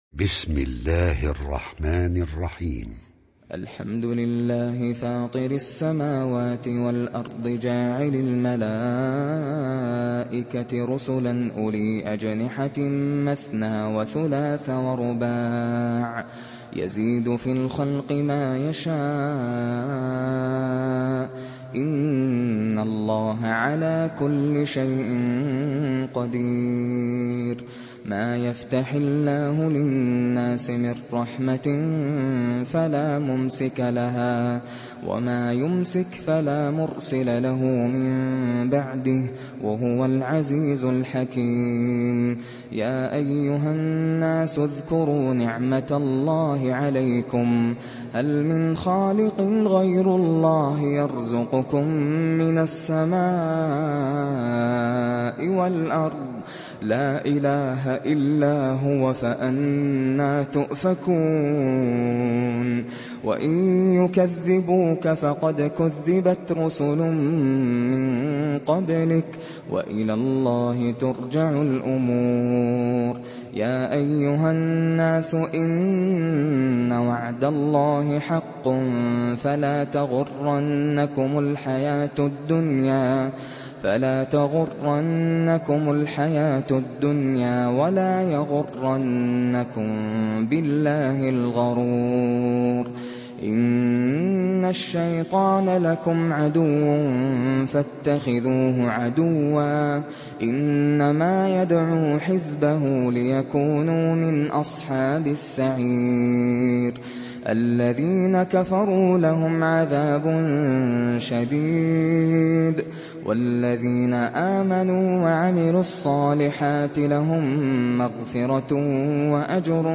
সূরা ফাতের ডাউনলোড mp3 Nasser Al Qatami উপন্যাস Hafs থেকে Asim, ডাউনলোড করুন এবং কুরআন শুনুন mp3 সম্পূর্ণ সরাসরি লিঙ্ক